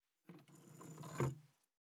398,机の上をスライドさせる,スー,ツー,サッ,シュッ,スルッ,ズズッ,スッ,コト,トン,ガタ,ゴト,カタ,ザッ,ヌルッ,キュッ,ギギッ,シャッ,スリッ,ズルッ,シャー,
効果音